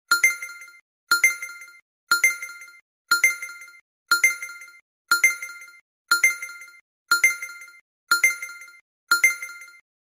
Countdown 10 to 0 | sound effects free download
Countdown 10 to 0 | Lego Inspired